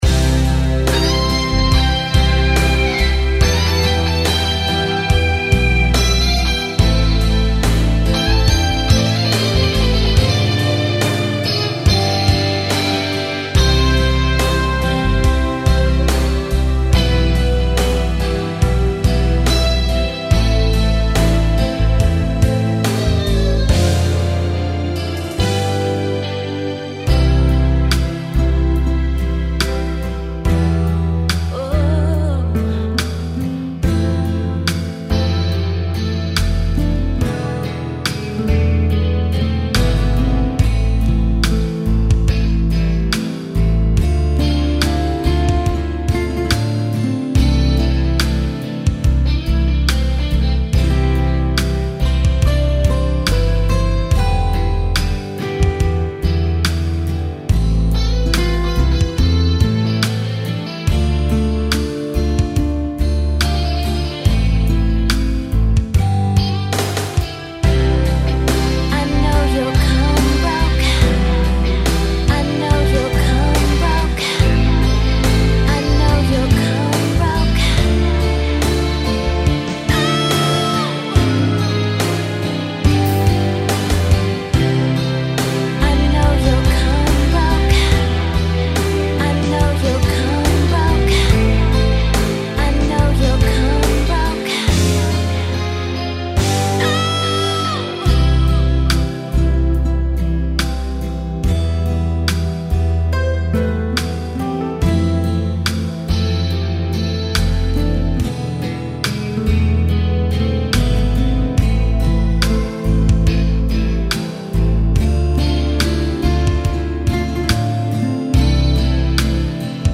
Adagio [70-80] plaisir - - ballade - calin - douceur